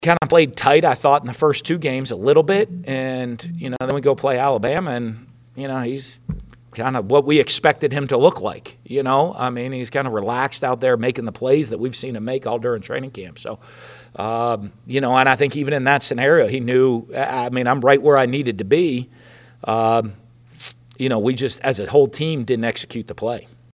In a recent press conference, Florida Gators head coach Dan Mullen discussed the football game against Alabama this past weekend and what is to come.